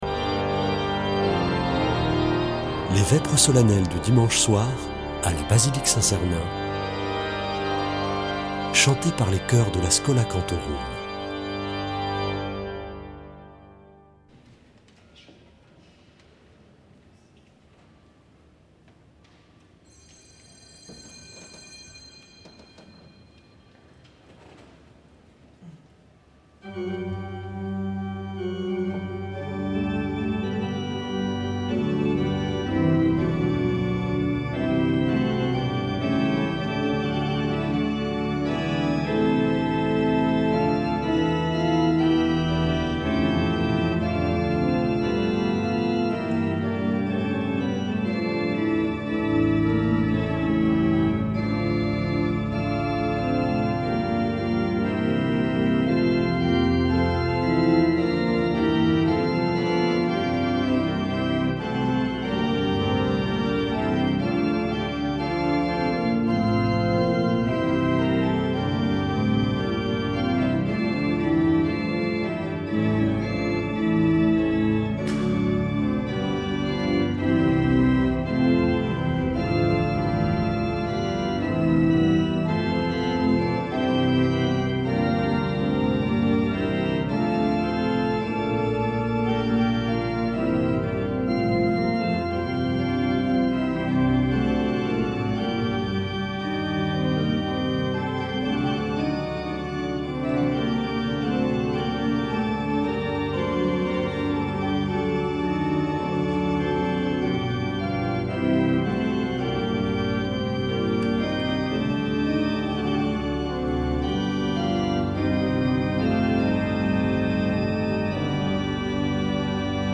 Une émission présentée par Schola Saint Sernin Chanteurs